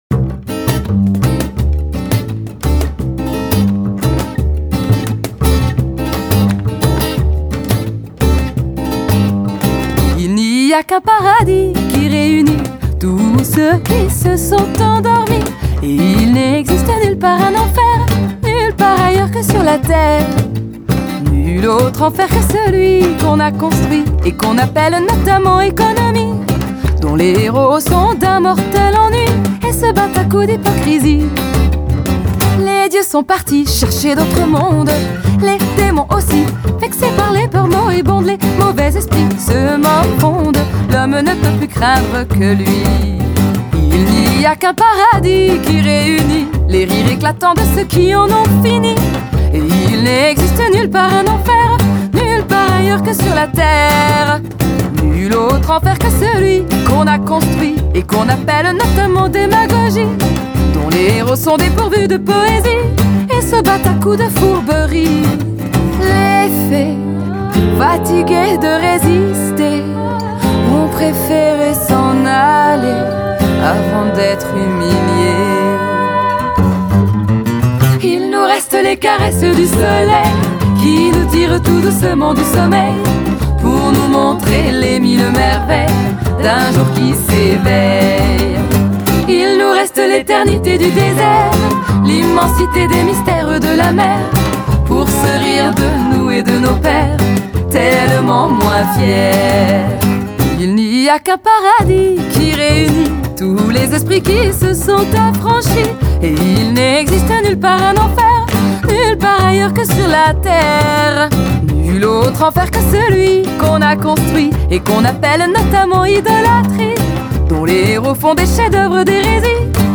Un groupe acoustique composéd’une chanteuse
guitariste
contrebassiste